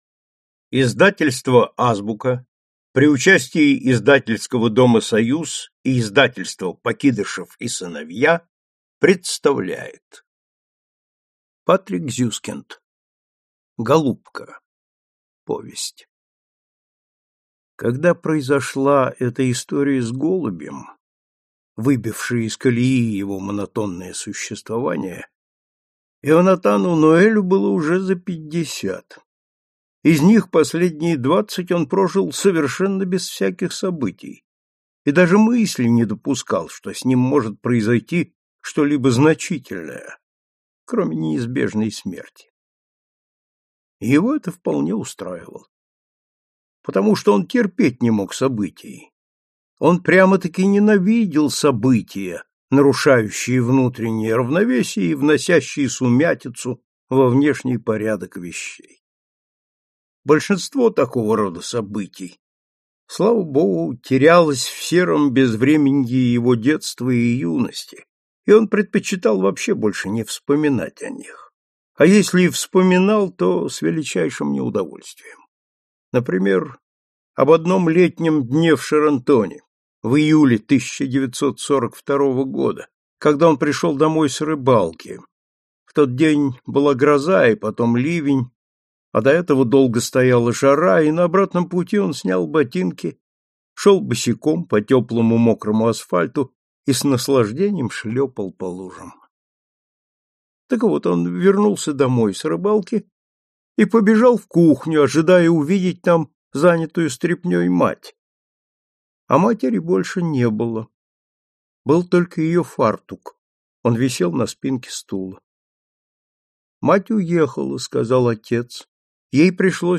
Аудиокнига Голубка. Три истории и одно наблюдение. Контрабас | Библиотека аудиокниг